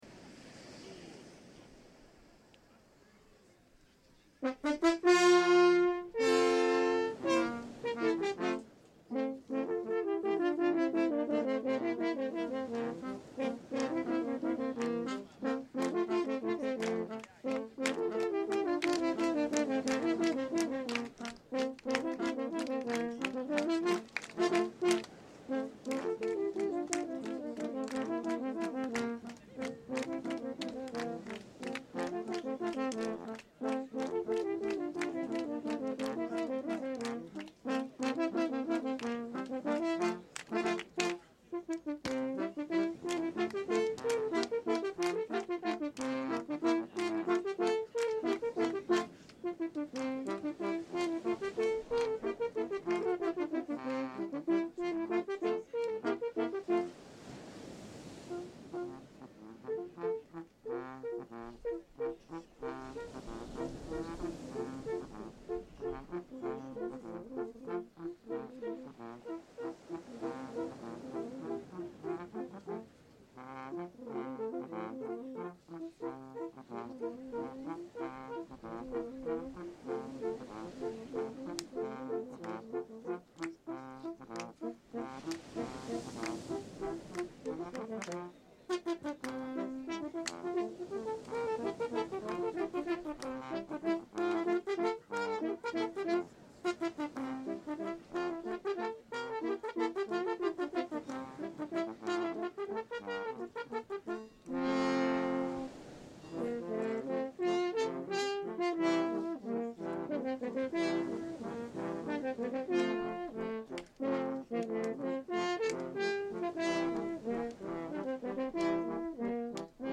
La terza serata, svoltasi sulla spiaggia, si è aperta con un coro di “giardinofoni”, seguito da pezzi suonati da due distinit grupi di corni.
quartetto di corni
Tutte le serate sono state seguite da un folto pubblico, formato non solo dai partecipanti al simposio ma anche da tanti cittadini di S.Agata.